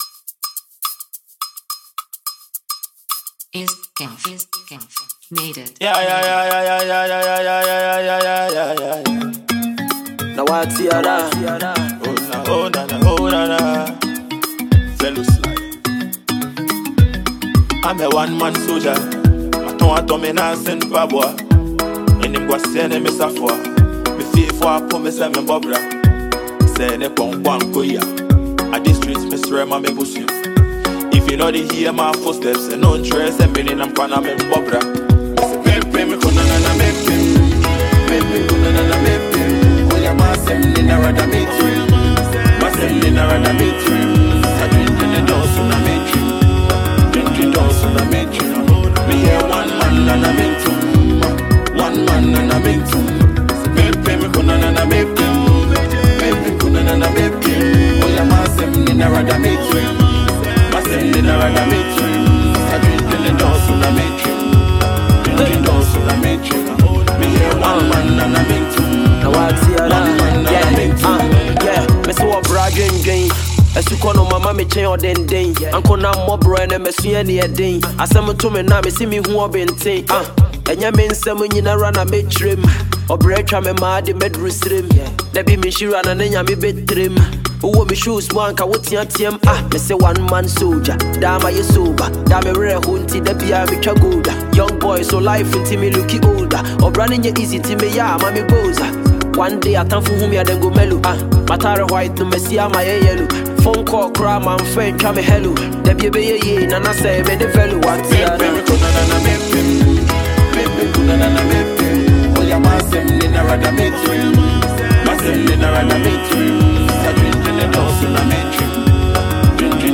Stream this latest song and enjoy the Afrobeat vibes.